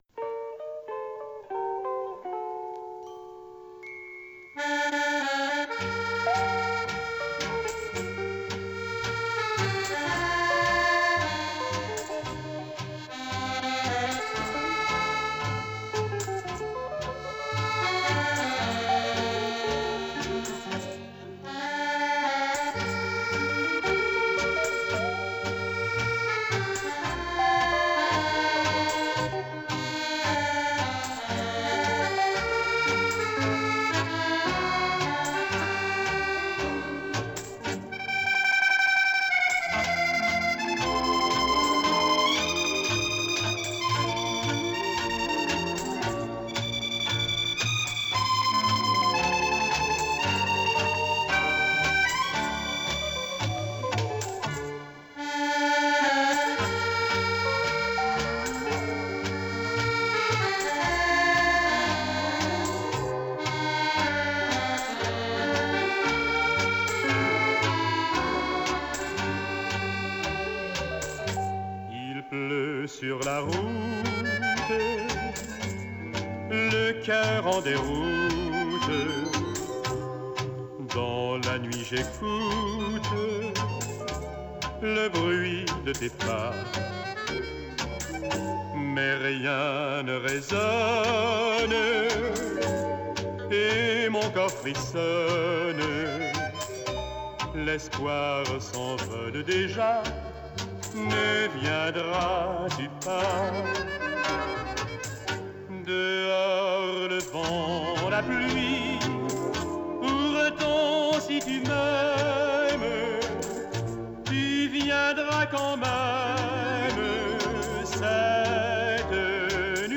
Старая запись.